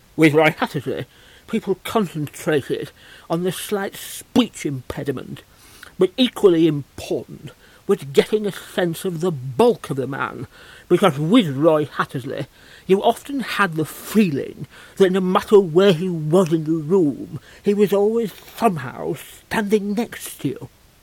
Steve Nallon's impression of Roy Hattersley